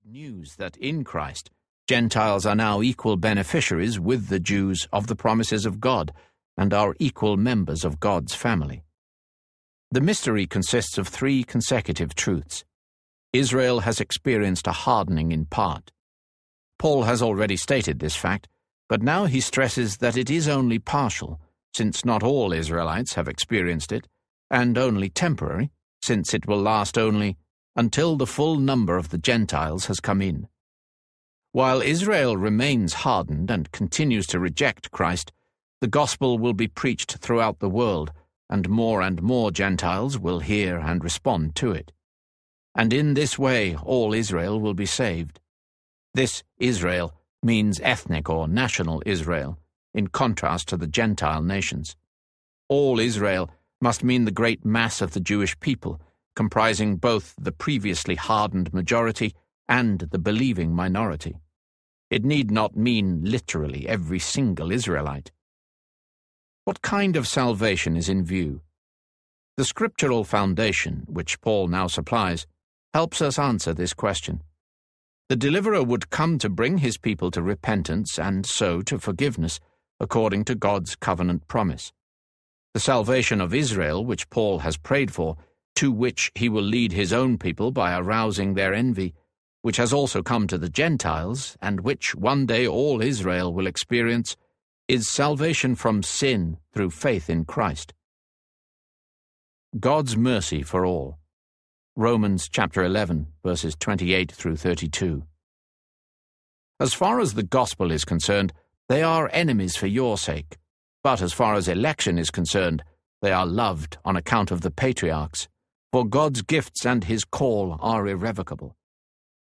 Reading Romans with John Stott, Volume 2 Audiobook
Narrator
3.5 Hrs. – Unabridged